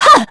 Scarlet-Vox_Attack1.wav